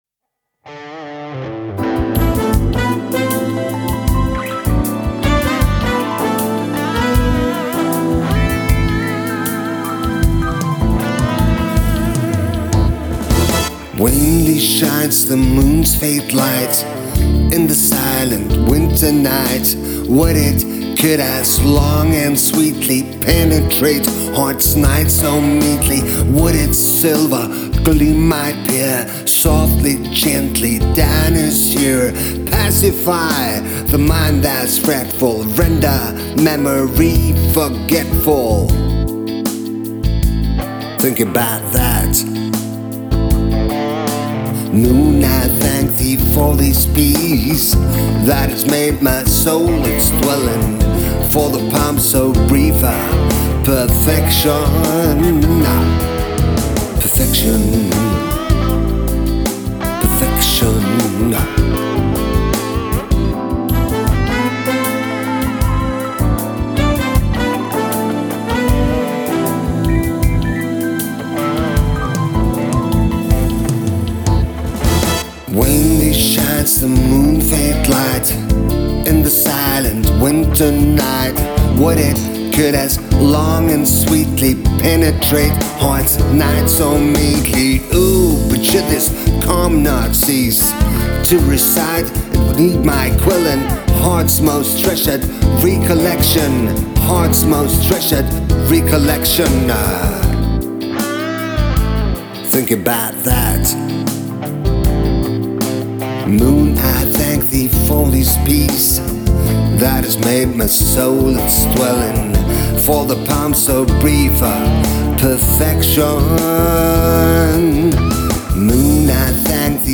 gitarer og programmering